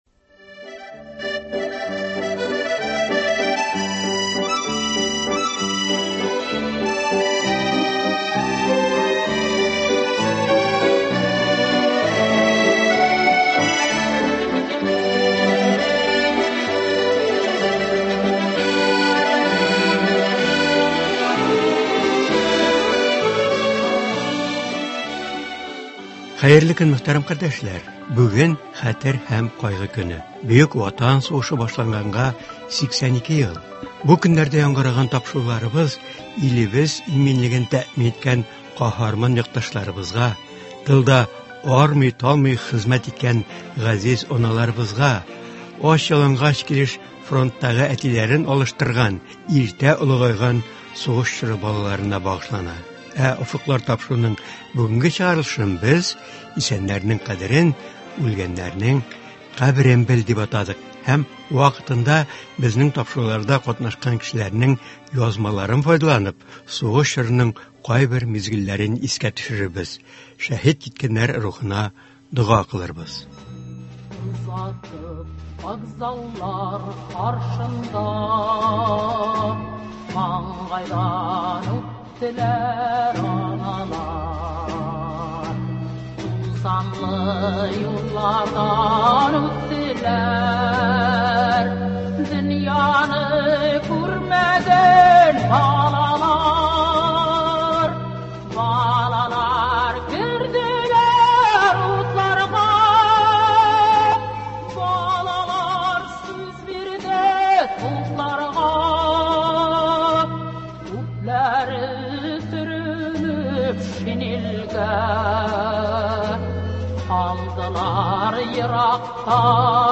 Бөек Ватан сугышы башлануга багышлап әзерләнгән тапшыру. Программада сугыш ветераннары, тыл хезмәтчәннәре катнаша.